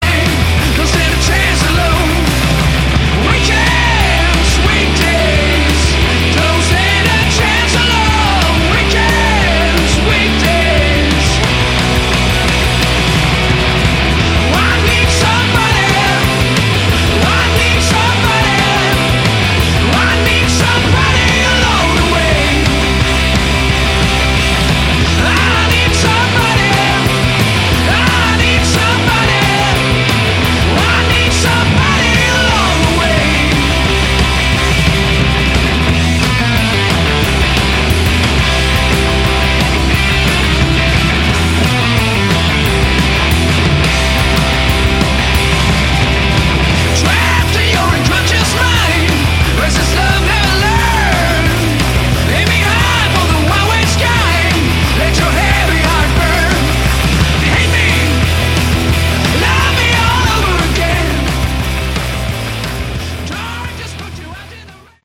Category: Hard ROck
vocals, guitar
drums
bass